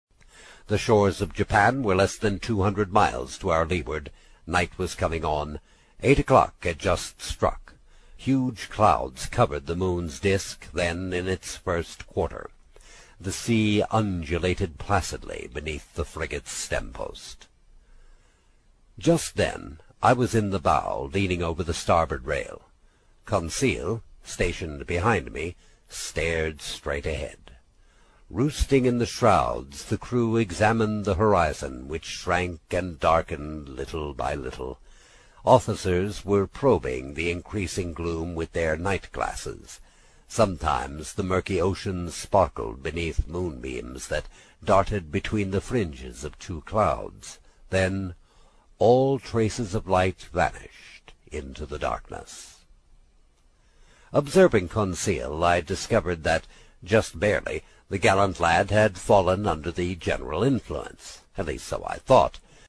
在线英语听力室英语听书《海底两万里》第57期 第5章 冒险活动(12)的听力文件下载,《海底两万里》中英双语有声读物附MP3下载